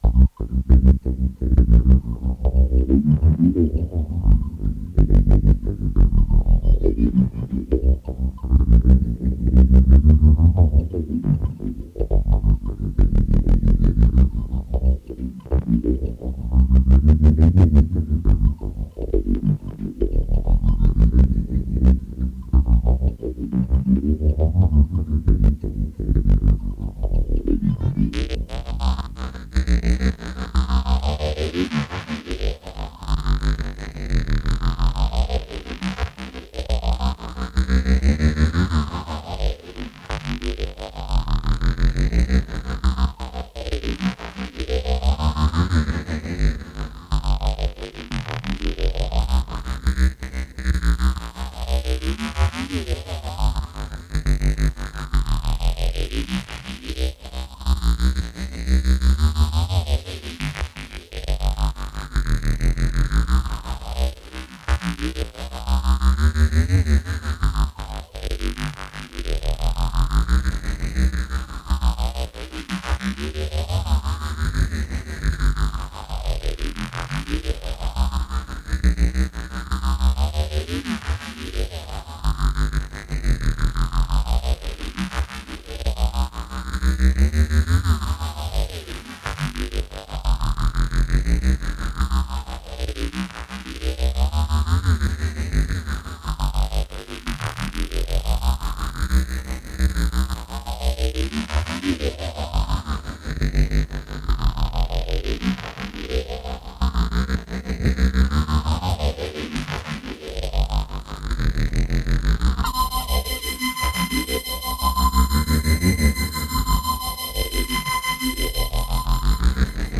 Effektprozessor Titanberg Falkenstein Gerettet Orga Seelenflug Emotion Weltraum Erwacht Orga2 Liebes-Rache Ohrenbeisser Voiceinspector Jungel Erstellt wuren die meißten Lieder mit Korg Electribe EA-1 und Sampler AKAI S-700 mit 6 Kanal. Die Lieder wurden in den 90er Jahren erstellt und auf Kassette aufgenommen.
Die meißten wurden Live gespielt und waren mir zu lang oder zu monoton.